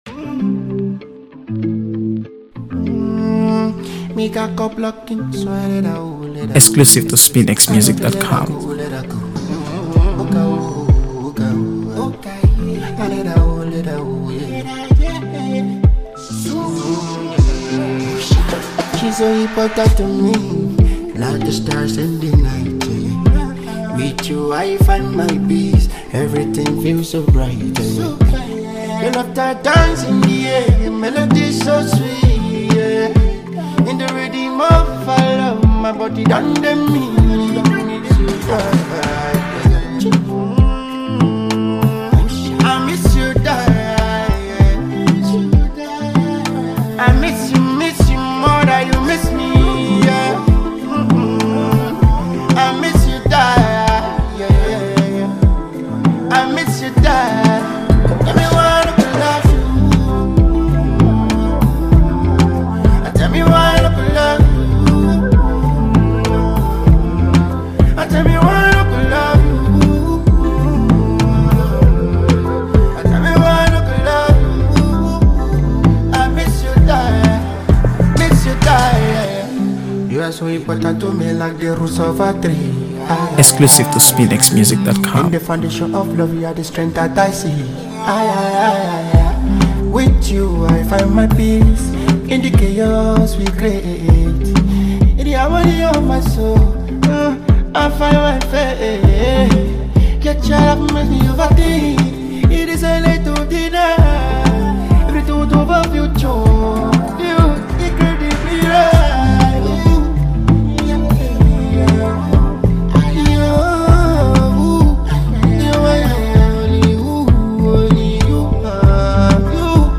AfroBeats | AfroBeats songs
With his smooth vocals and heartfelt delivery
blends catchy melodies with a deeply personal message